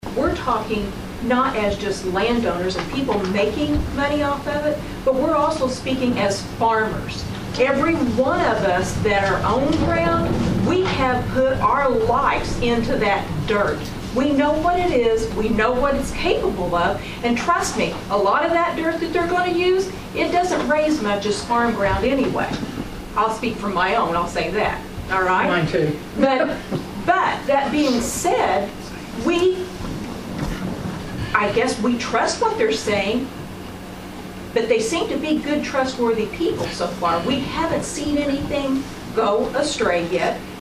Another White County Board Public Hearing; Another Lively Discussion